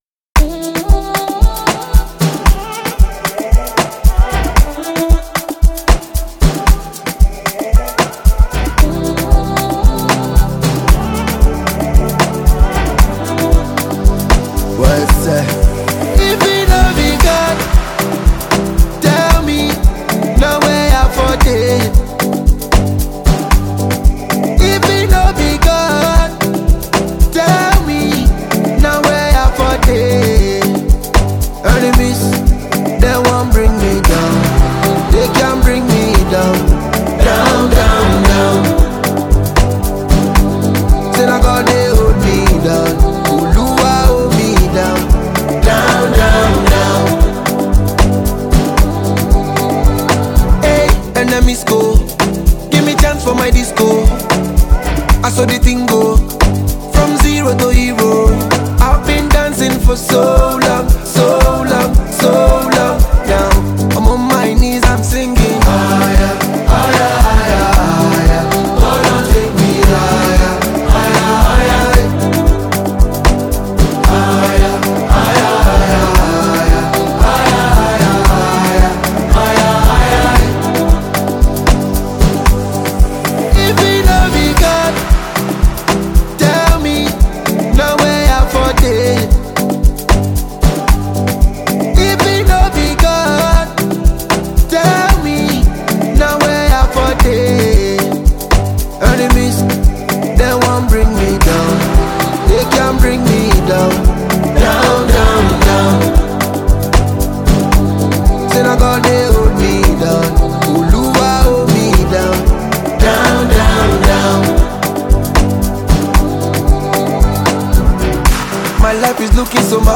Nigerian dancehall singer and songwriter